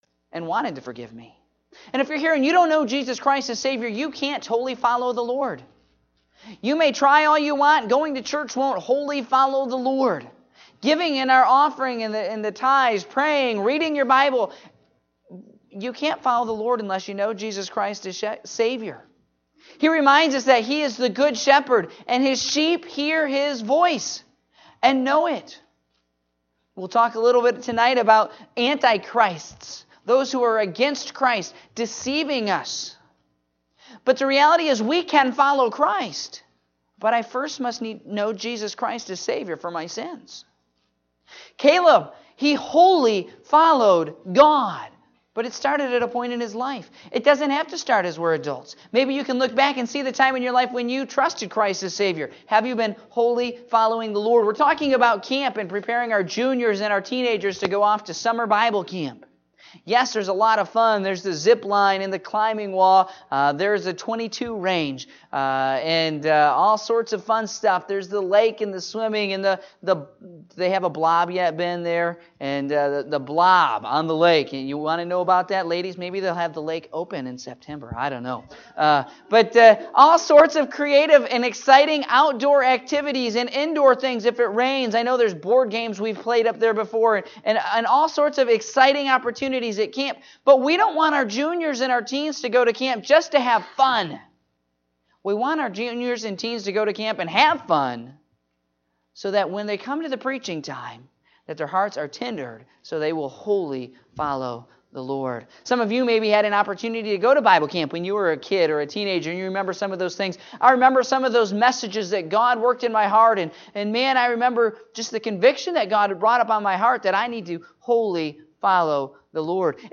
Morning Service (06/11/2017)